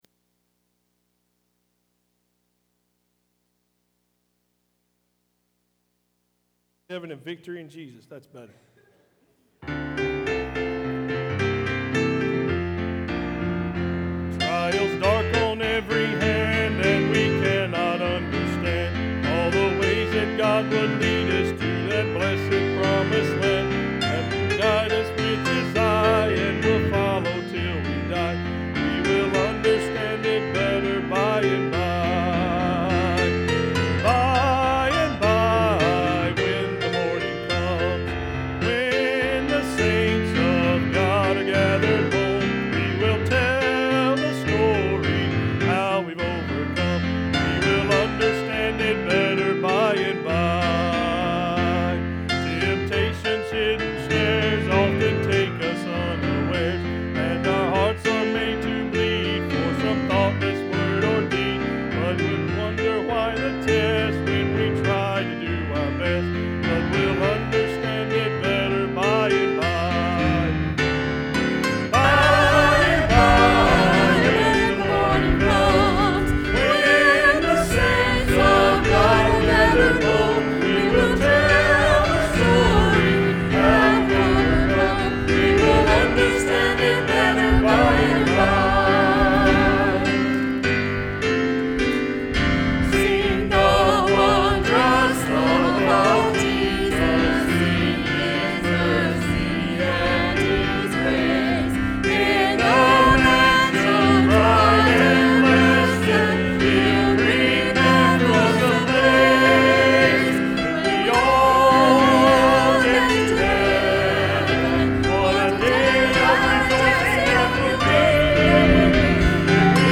First Baptist Church Childersburg SUNDAY SERVICES
10-1-17 worship.mp3